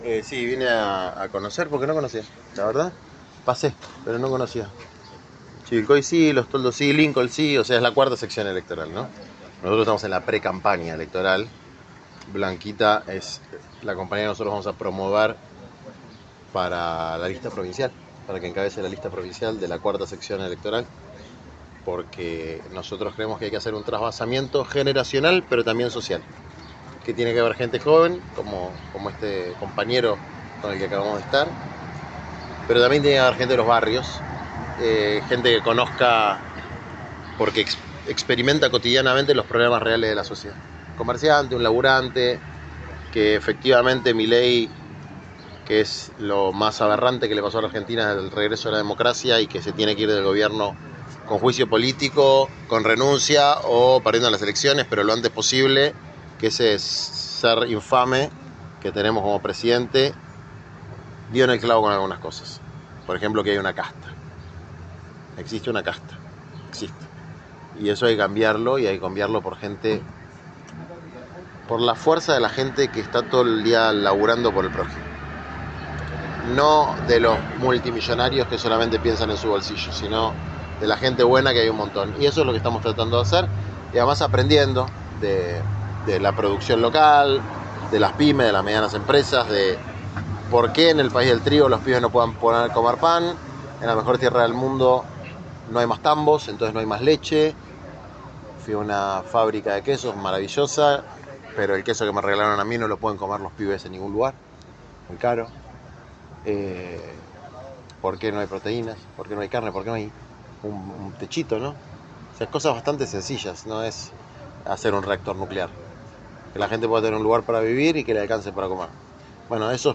Lo expresó Juan Grabois, en la visita que hizo este viernes a la ciudad de Chacabuco.
Grabois fue consultado por el periodismo local sobre varios temas, entre ellos, la interna que atraviesa a Unión por la Patria en la provincia de Buenos Aires.